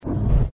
doorclose.mp3